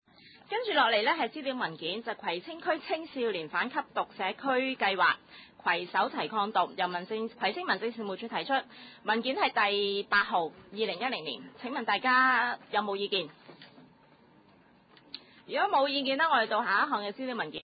葵青區議會第六十三次會議